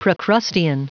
Prononciation du mot procrustean en anglais (fichier audio)